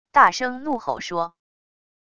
大声怒吼说wav音频